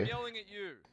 Yelling at you